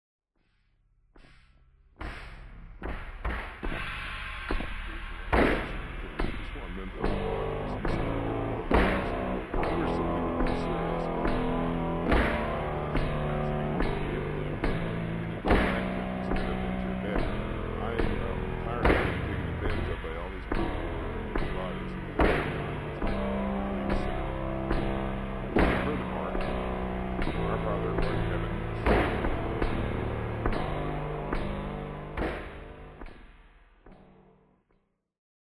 hard rock
Velocizzato